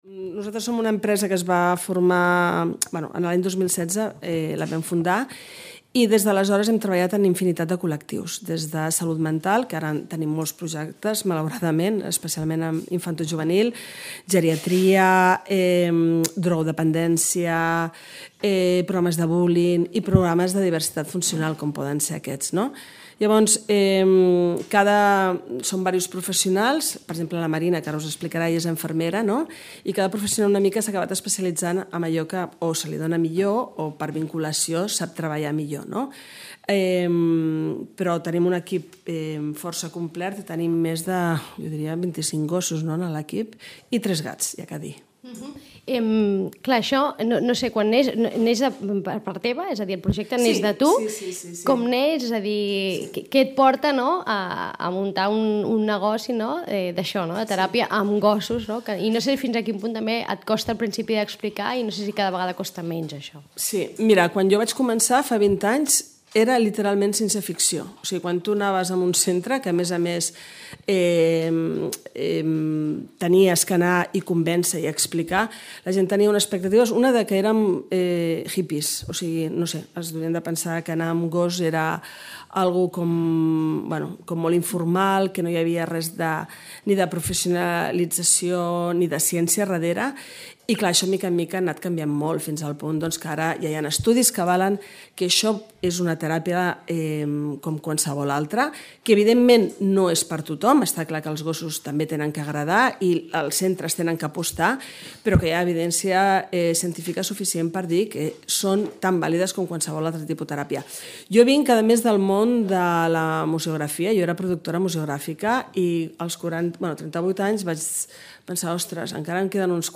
En Balto, un golden de poc més d’un any, és el nou amic d’alguns dels usuaris de la Fundació Ave Maria, des de fa dos anys el centre compta amb una teràpia amb gossos a càrrec de les professionals d’El Racó de Milu, una empresa dedicada a les intervencions assistides amb gossos. Hem pogut assistir a una de les sessions que han realitzat a l’Ave Maria amb un grup d’usuàries per comprovar insitu com persones a qui els costa connectar a través dels gossos participaven del grup i de l’activitat proposada.